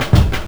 FILLDRUM06-L.wav